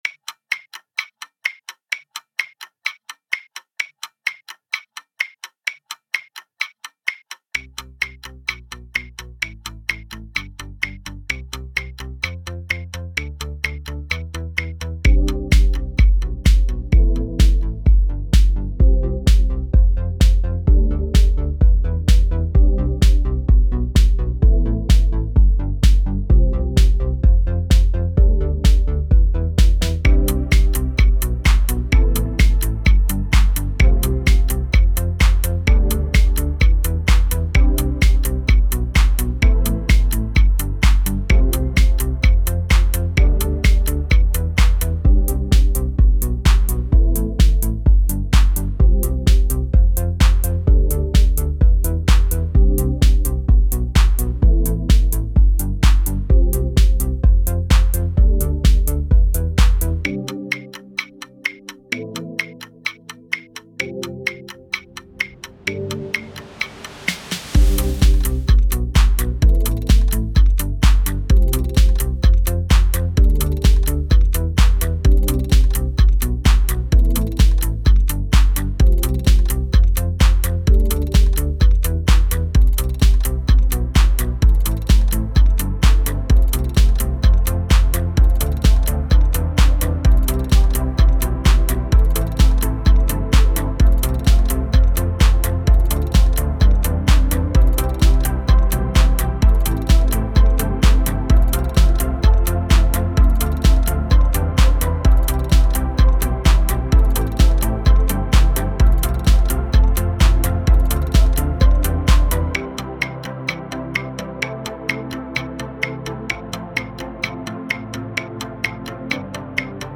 That song was created quite a few years ago, and was quite outdated.